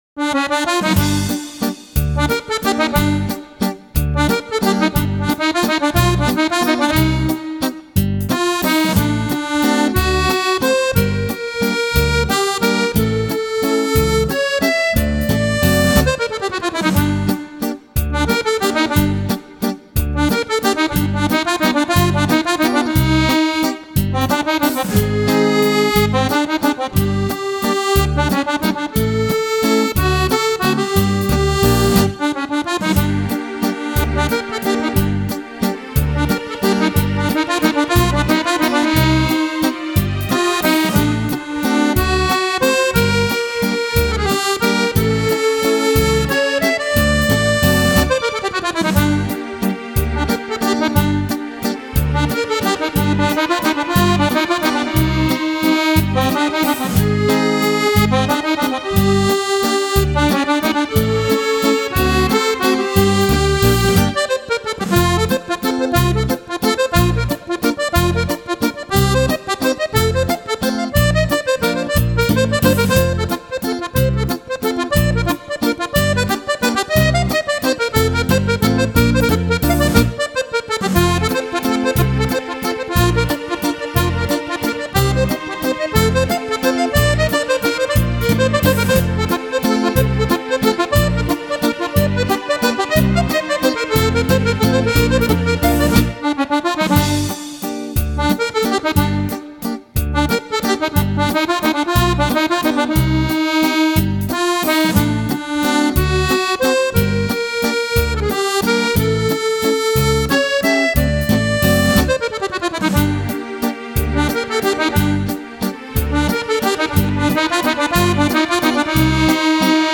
Valzer
Antologia di 51 successi per fisarmonica